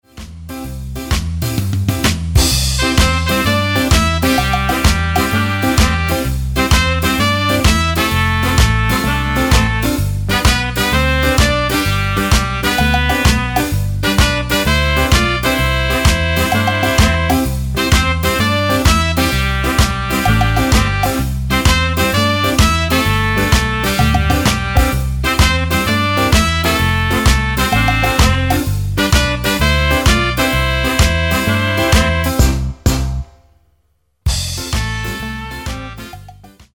Tonart:C ohne Chor